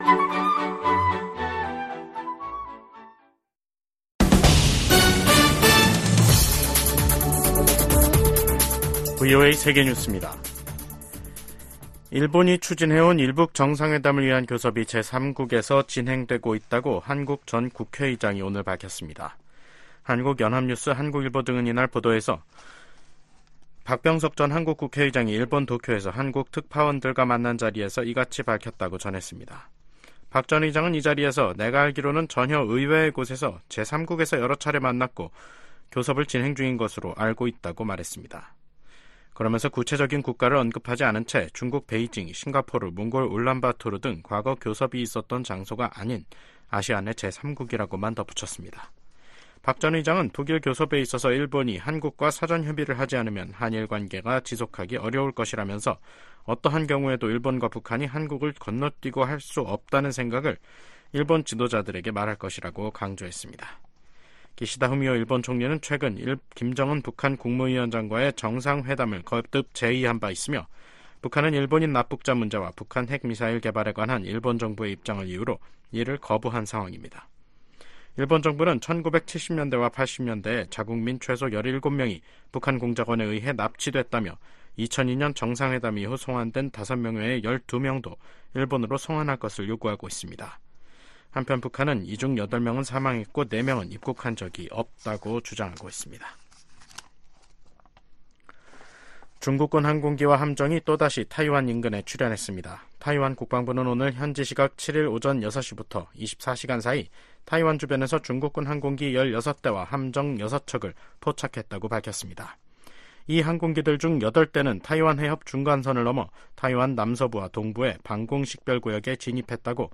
VOA 한국어 간판 뉴스 프로그램 '뉴스 투데이', 2024년 5월 8일 2부 방송입니다. 도널드 트럼프 전 대통령은 자신이 대통령에 당선되면 한국이 주한미군 주둔 비용을 더 많이 부담하지 않을 경우 주한미군을 철수할 수 있음을 시사했습니다. 러시아 회사가 수천 톤에 달하는 유류를 북한으로 운송할 유조선을 찾는다는 공고문을 냈습니다.